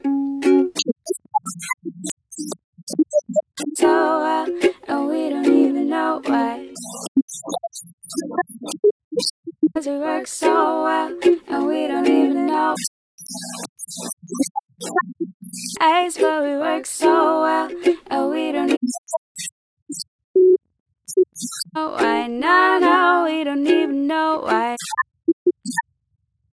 Audio Is Distorted After Exported
My audio from Audacity sounds severely distorted and quiet when I post it on YouTube or other places. The only time it sounds correct is when played on YouTube through headphones.
That will sound OK on stereo headphones, but on devices with mono playback the anti-phase channels will cancel each other out , ( destructive-interference ), and all you will hear are compression-artifacts, which are a “severely distorted” version …